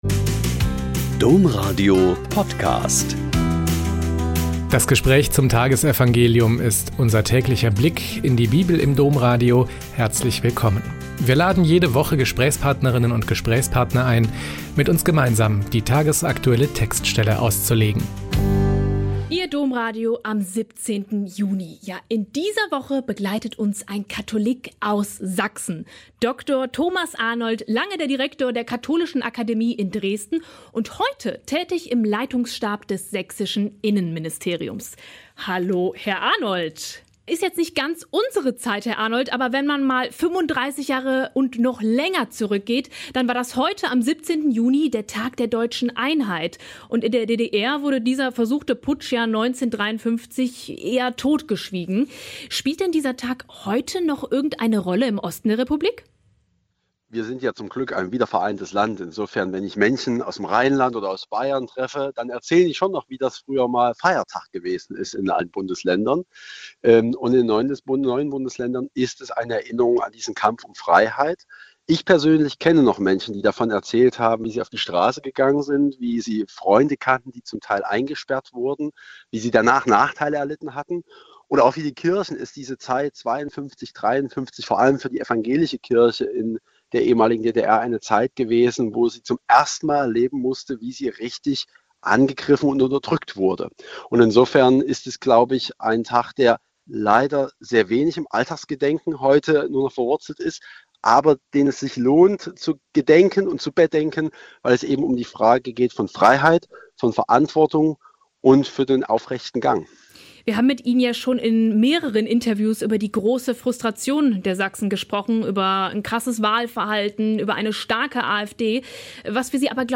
Mt 5,43-48 - Gespräch